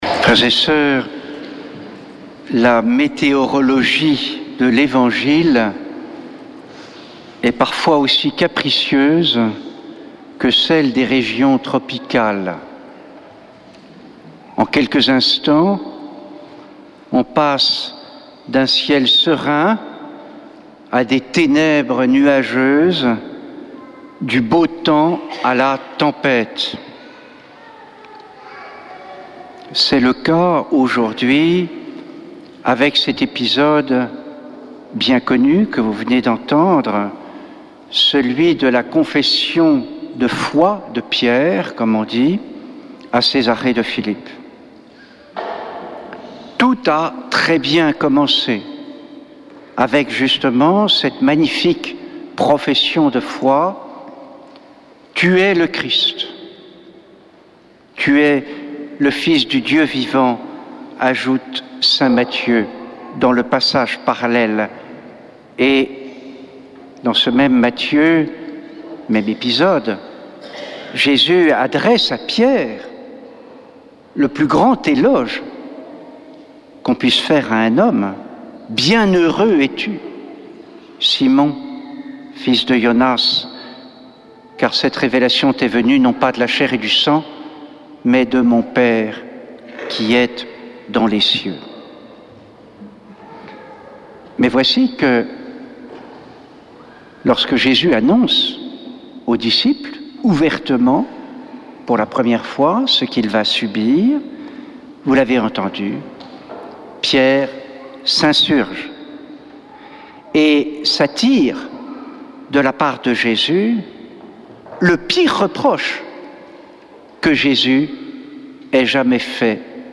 Messe depuis le couvent des Dominicains de Toulouse
Homélie du 15 septembre